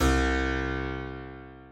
Harpsicord
c2.mp3